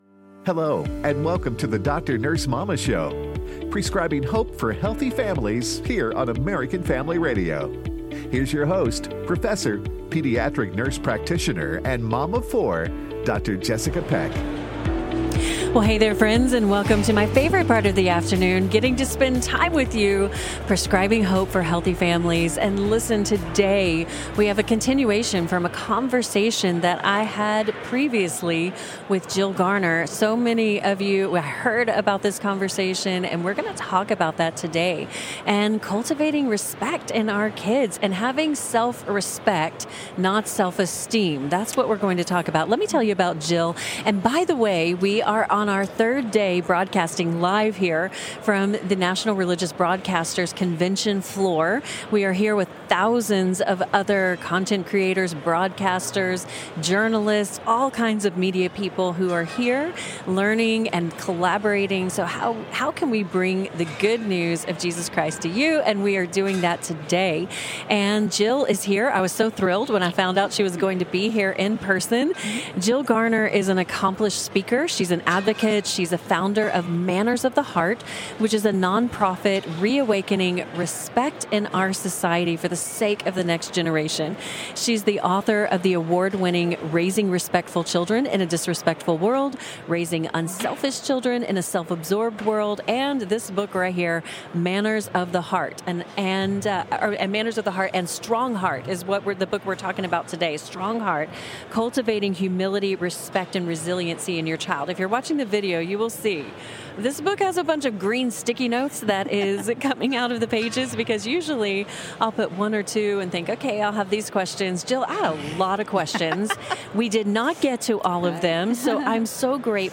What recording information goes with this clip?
Live from NRB.